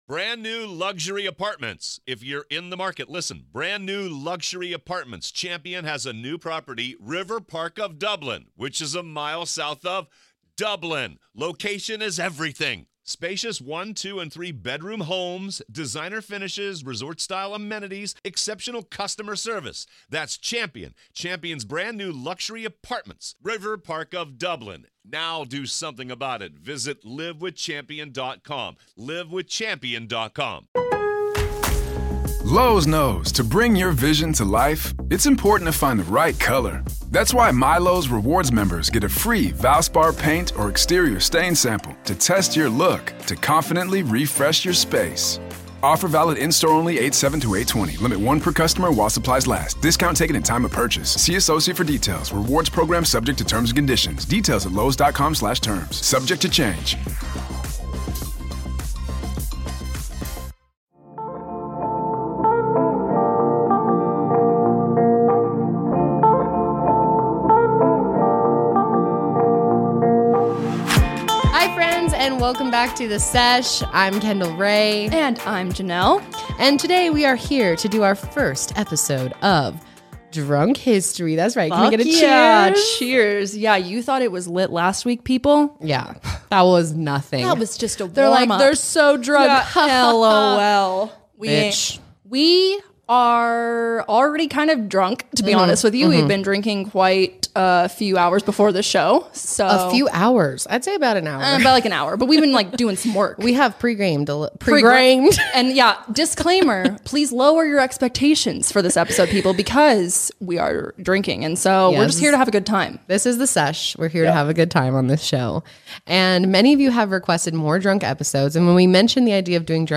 This week the cousins host their own "National Sesh Dog Show" and rank their behavior. They also test out their first "Drunk History" Sesh by enlightening you on the truth about Thanksgiving.